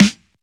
Tight Sizzle Snare.wav